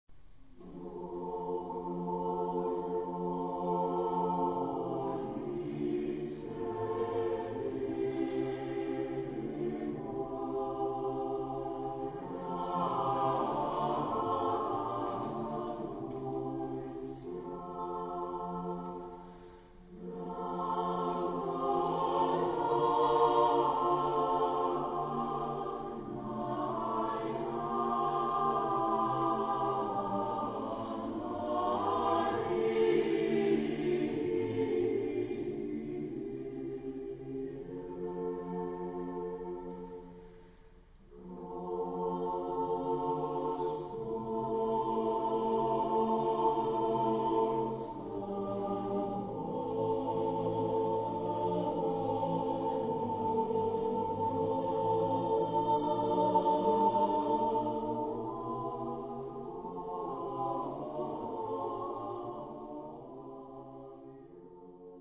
SATB (4 voices mixed) ; Score with keyboard for rehearsal only.
Orthodox liturgical hymn.
Consultable under : 20ème Sacré Acappella Location of the CD: CD-0007